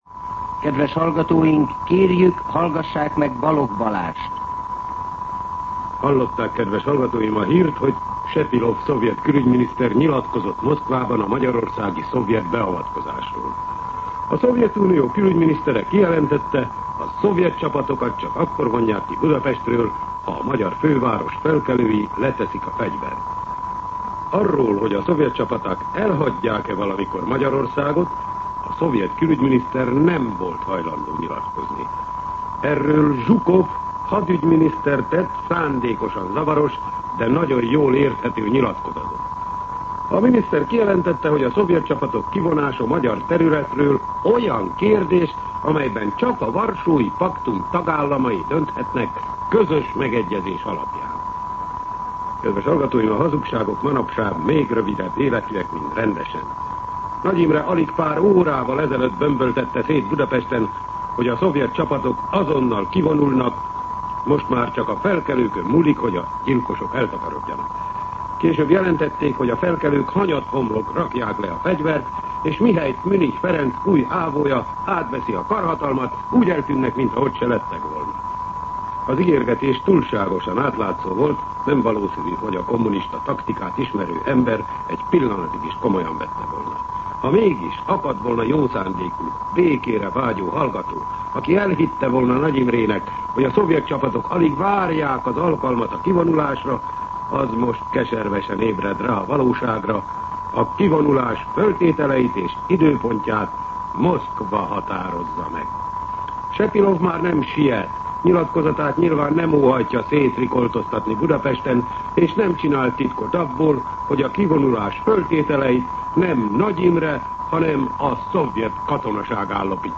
Rendkívüli kommentár
MűsorkategóriaKommentár